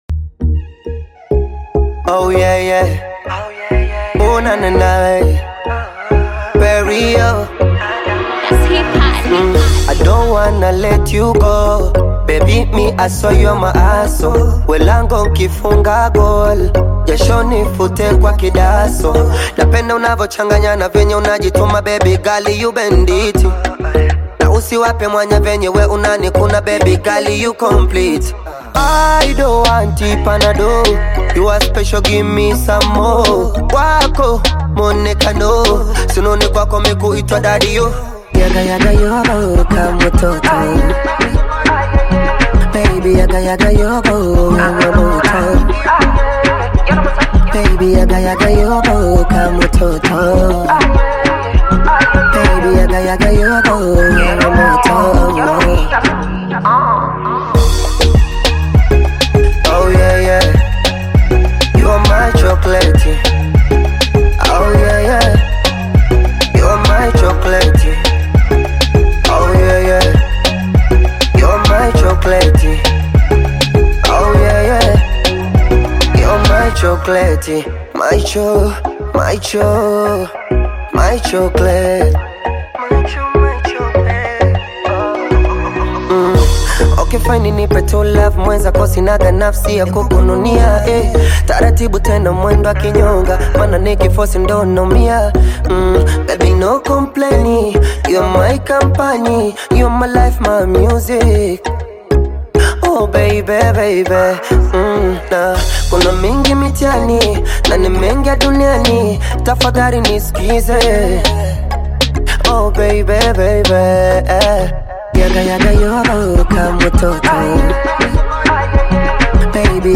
Bongo Flava
heartfelt love song
Through poetic lyrics and soulful melodies